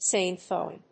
/ˈseɪnfɔɪn(米国英語)/